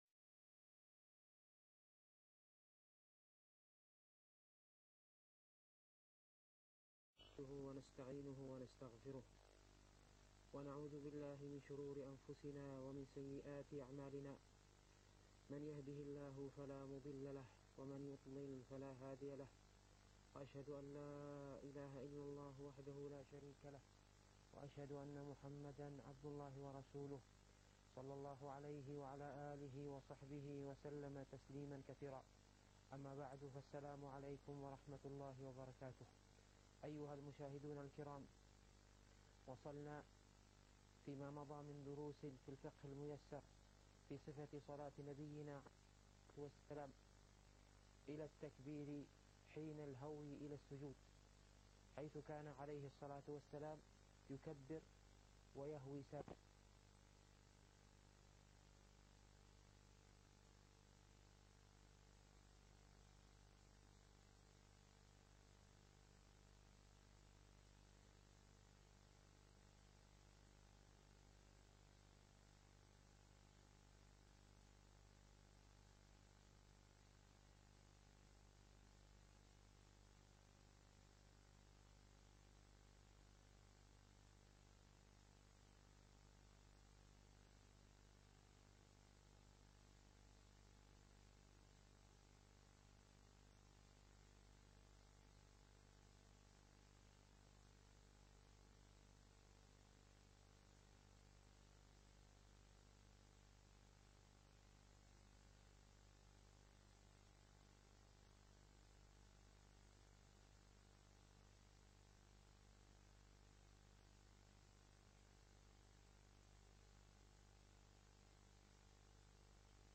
الفقه الميسر الدرس السابع والعشرون